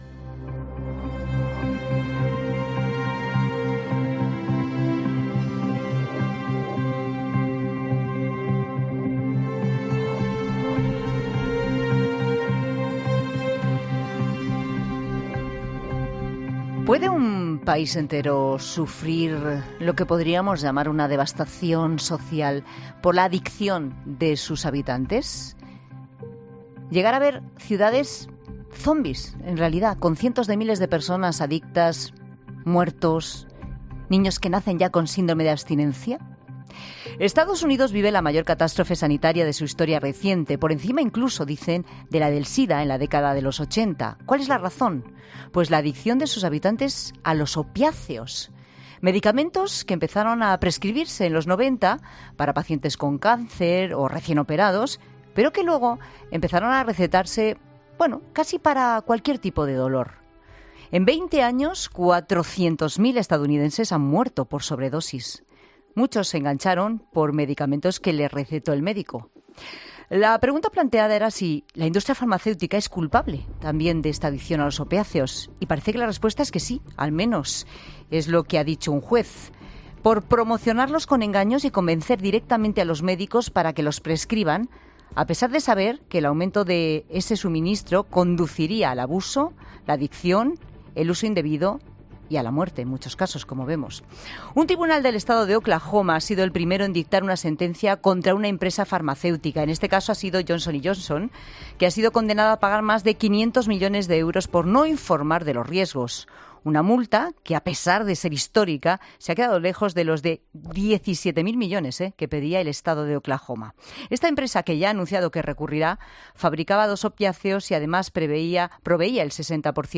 El médico especialista en adiciones